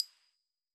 Sound / Effects / UI / Modern1.wav